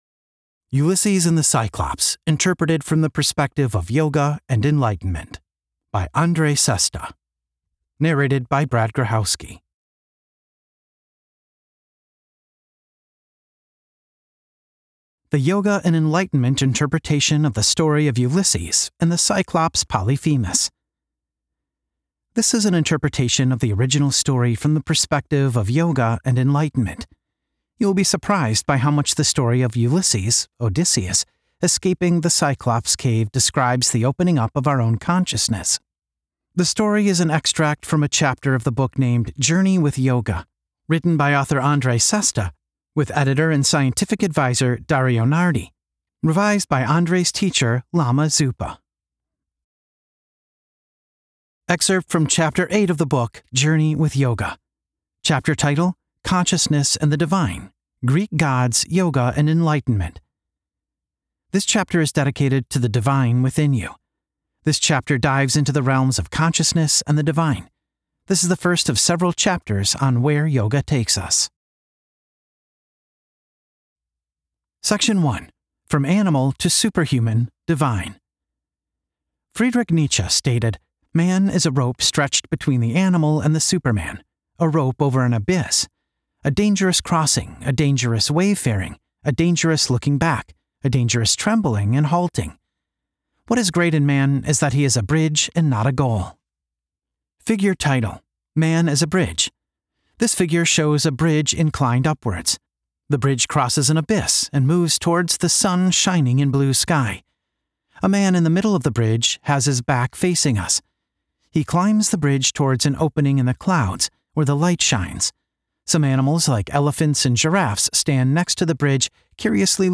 The story narrated in the mp3 audiobook and the mp4 video are slighly summarised when compared to the chapter excerpt in html format.
sample_chapter_audio_book.mp3